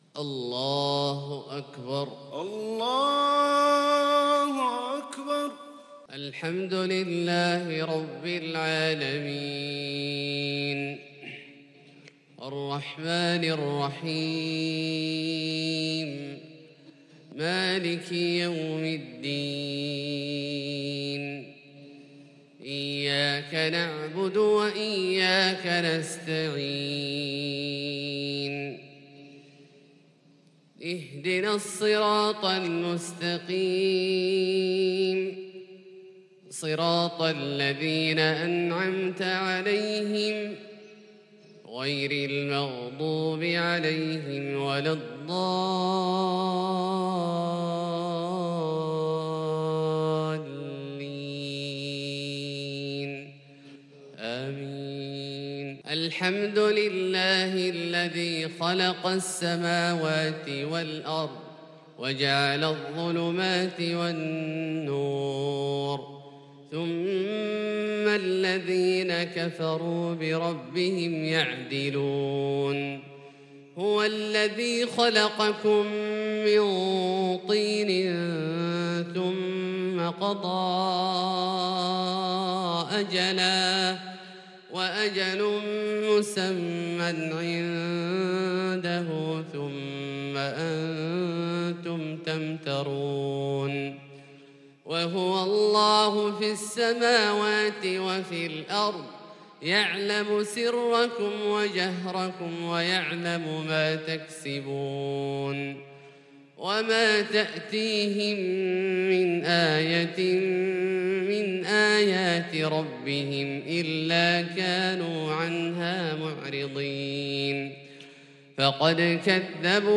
صلاة الفجر للقارئ عبدالله الجهني 9 ربيع الأول 1442 هـ
تِلَاوَات الْحَرَمَيْن .